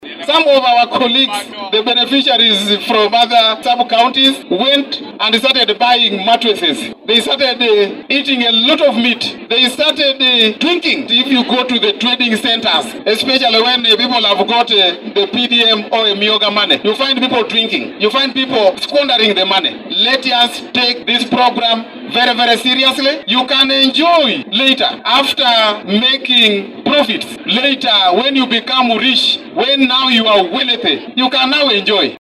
In a spirited call to action, residents of Arua district have been urged to wholeheartedly embrace the government's development programs. The impassioned plea was made by Geoffrey Okiswa, the Resident District Commissioner (RDC) of Arua, during his role as the chief guest at the 61st Independence Day celebration. Mr. Okiswa stressed the importance of actively participating in government initiatives, particularly the Parish Development Model, as a means to transform their communities into thriving economic hubs.